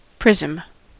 prism: Wikimedia Commons US English Pronunciations
En-us-prism.WAV